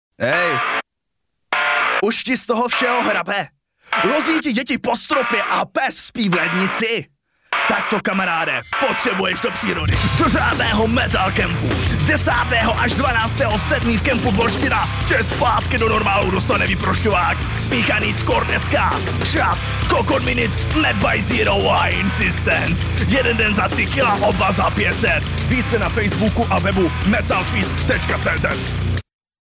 Mužský Hlas -Voiceover
Reklama do rádia.mp3